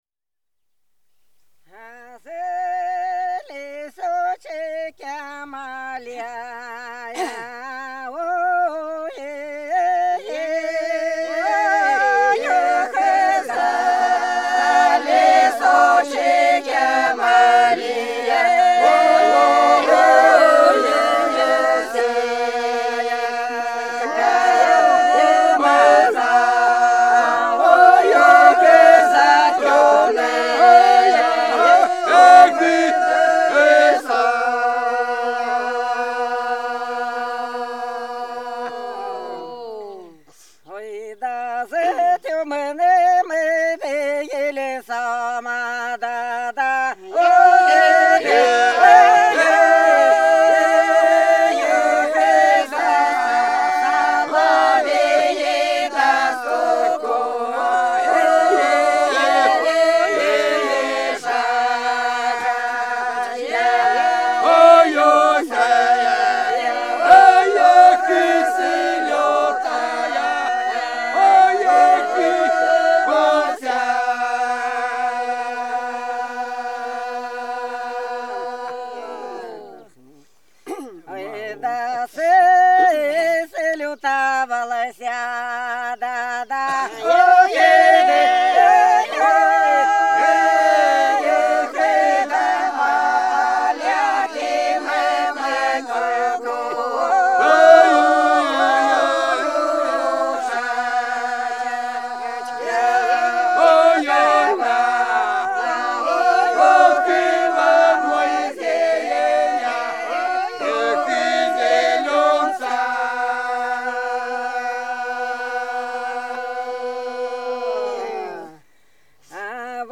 Белгородские поля (Поют народные исполнители села Прудки Красногвардейского района Белгородской области) За лесочком, лесом - протяжная, весновая